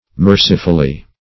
[1913 Webster] -- Mer"ci*ful*ly, adv.